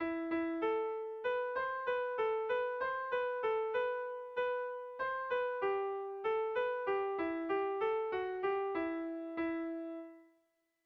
Erromantzea
AB